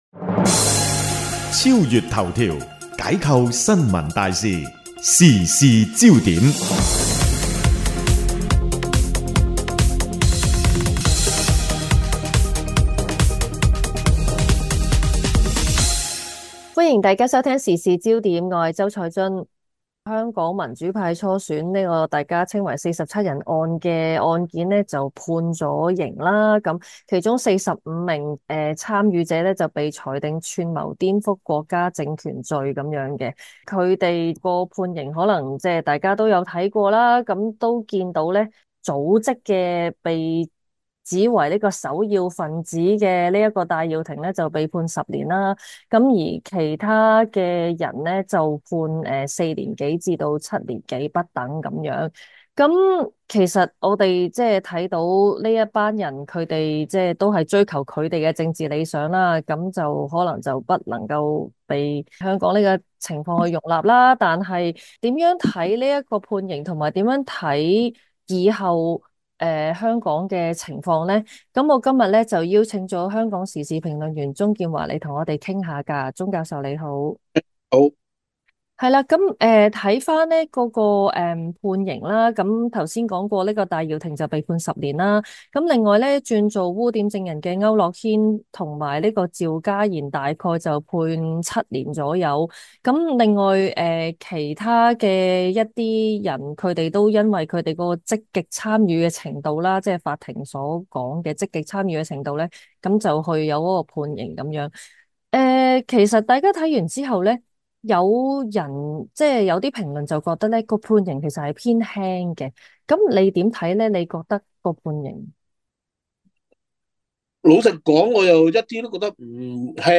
【有聲訪問】如何看初選案判決？後續有什麼應該關注？